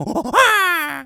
monkey_chatter_angry_02.wav